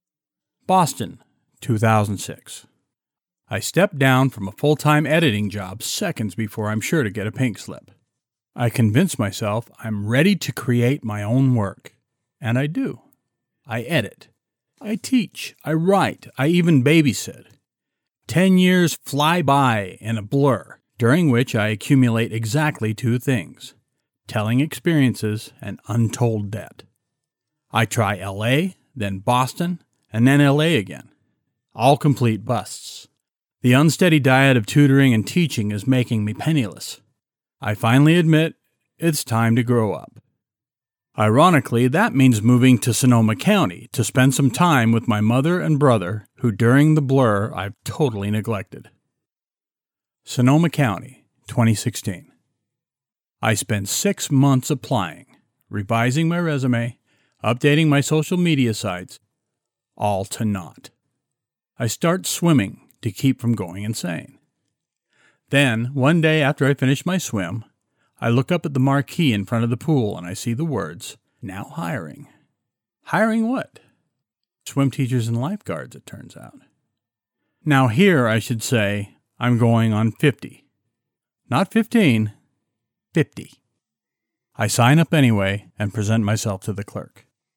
It’s early in the book and needs energy and humor in the reading.